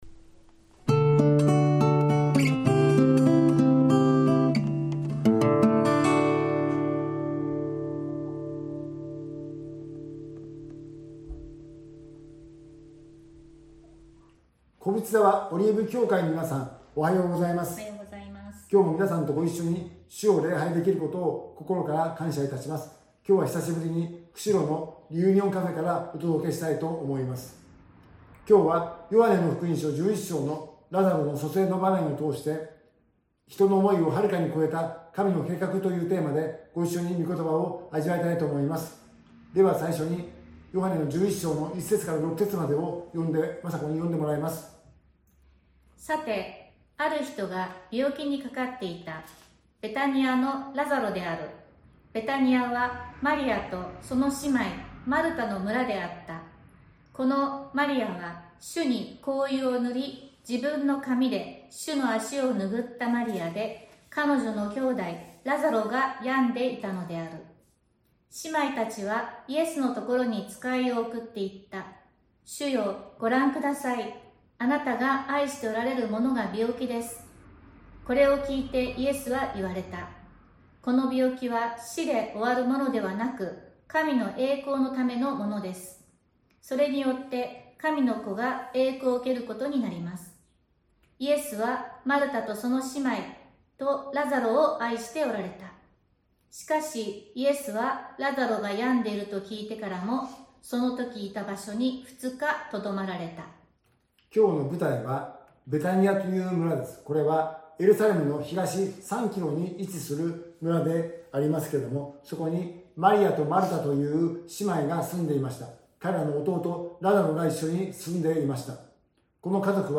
シリーズ: ゲストスピーカー